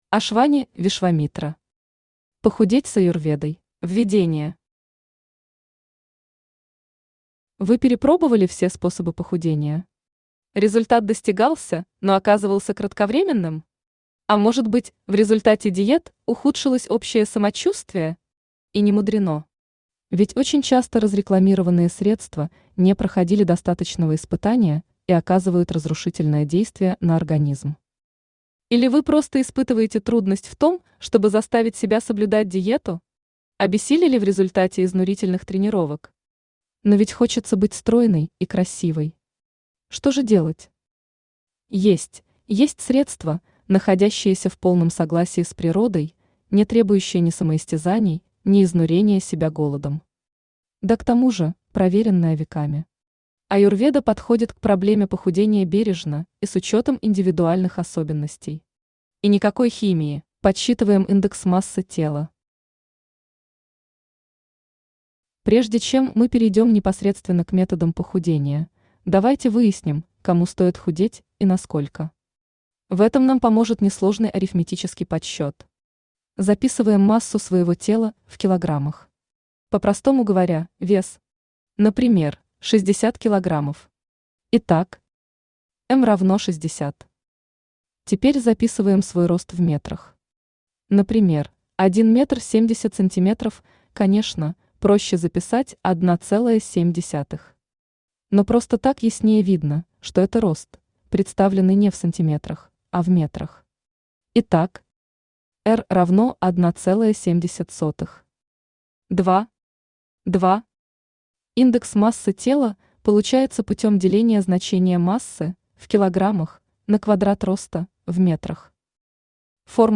Aудиокнига Похудеть с Аюрведой Автор Ашвани Вишвамитра Читает аудиокнигу Авточтец ЛитРес.